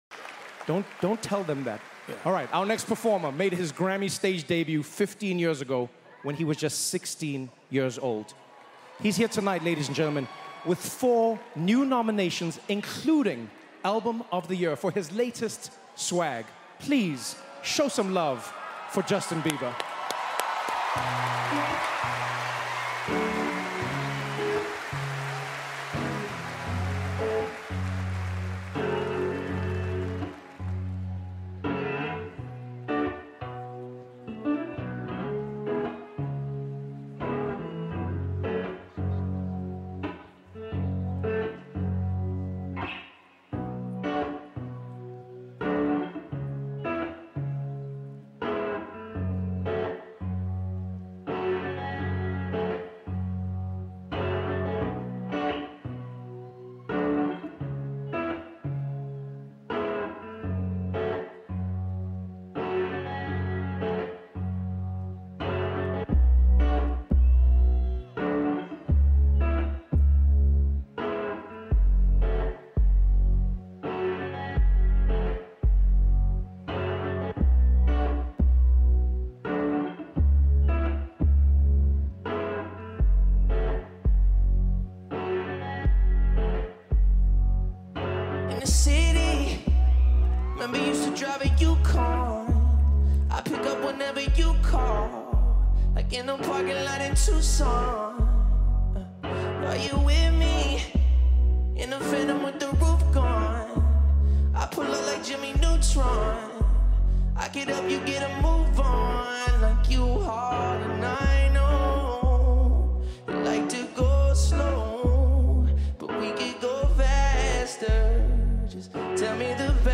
Award winning singer-songsmith